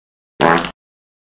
Радио и рингтоны » Звуки » Человеческие звуки » Звуки Goofy Ahh
Goofy Ahh пук